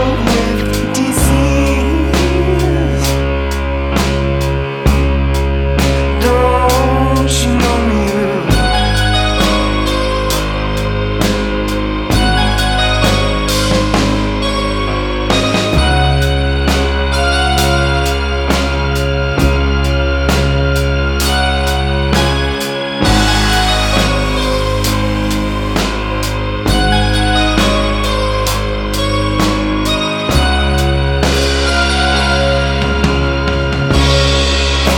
Alternative Indie Rock Rock
Жанр: Рок / Альтернатива